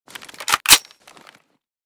g36_unjam.ogg